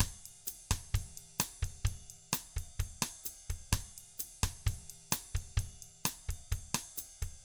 129BOSSAT3-R.wav